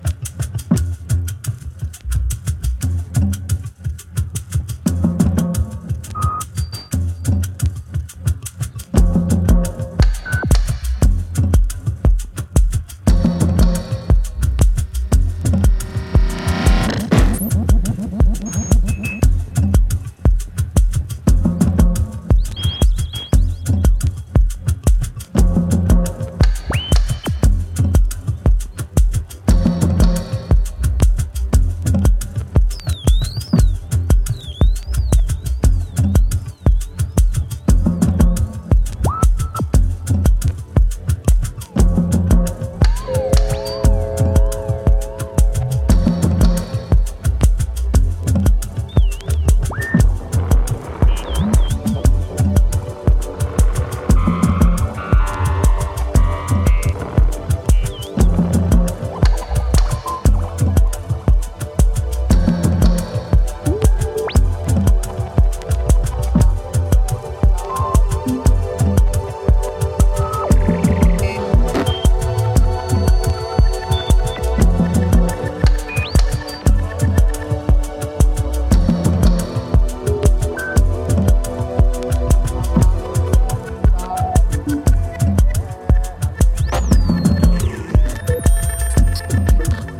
vintage synthesisers